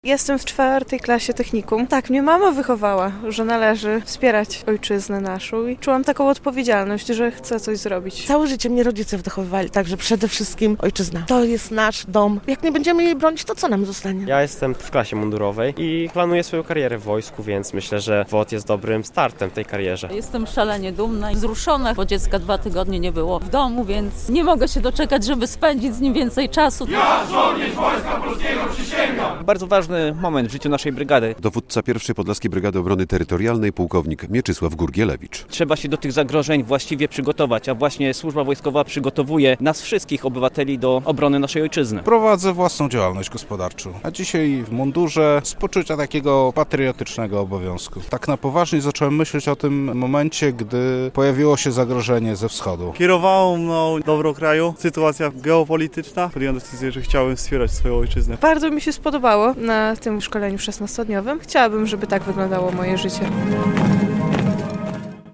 Blisko stu ochotników wstąpiło w szeregi 1. Podlaskiej Brygady Obrony Terytorialnej. Uroczysta przysięga odbyła się w jednostce wojskowej w Białymstoku.
Przysięga w podlaskie brygadzie WOT - relacja